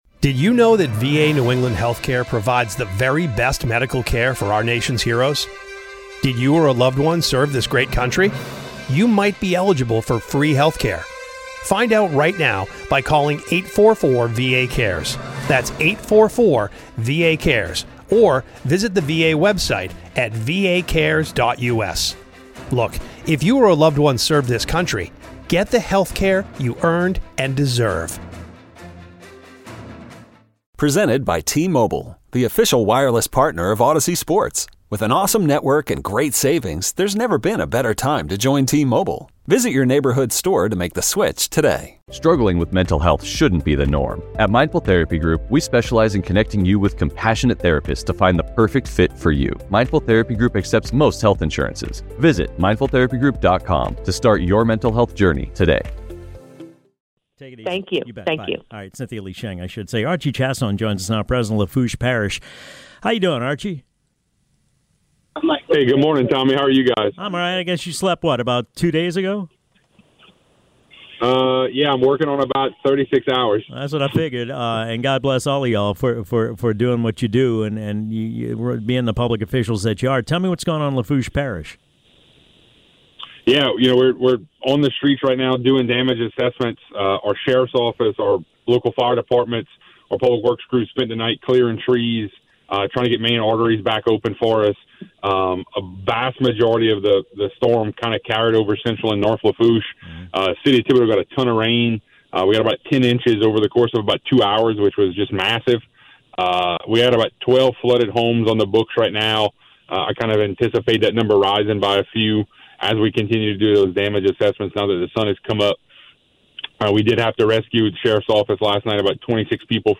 talks with Lafourche Parish President Archie Chaisson